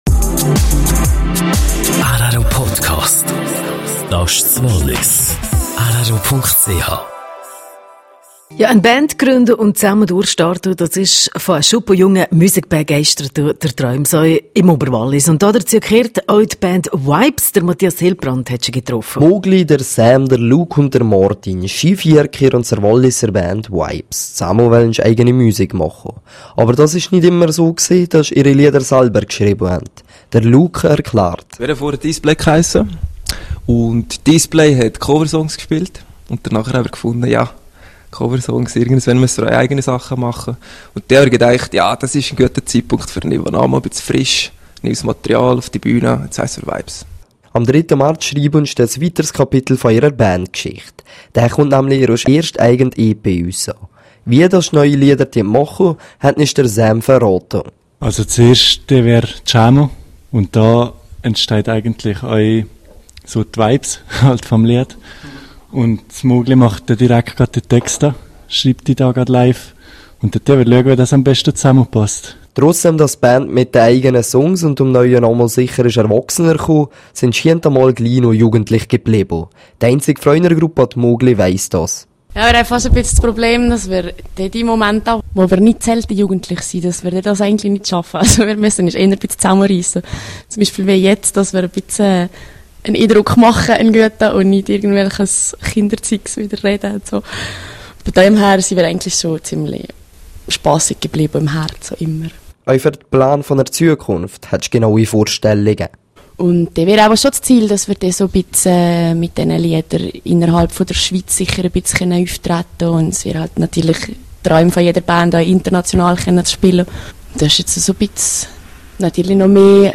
hm Vibez zu Gast bei rro: Beitrag über die Band und ihre Zukunft.